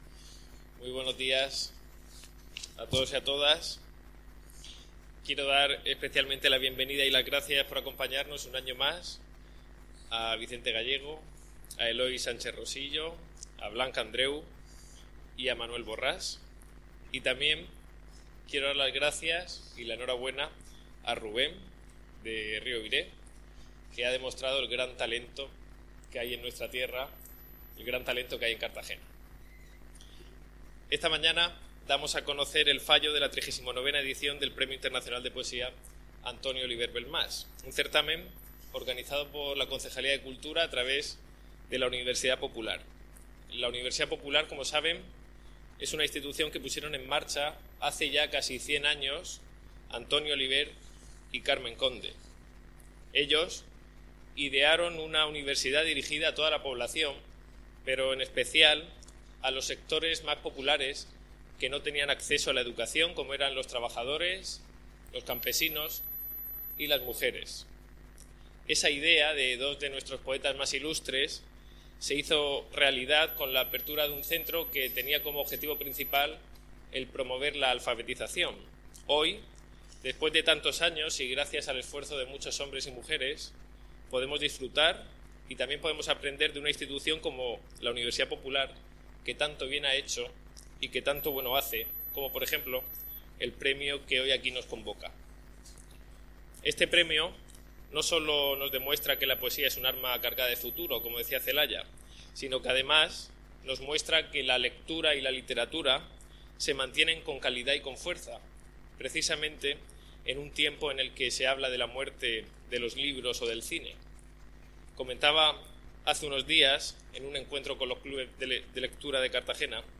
Declaraciones de Ignacio J�udenes
Así se ha dado a conocer este jueves 13 de noviembre en un acto celebrado en el Palacio Consistorial y al que ha asistido el concejal delegado de Cultura, Ignacio Jáudenes.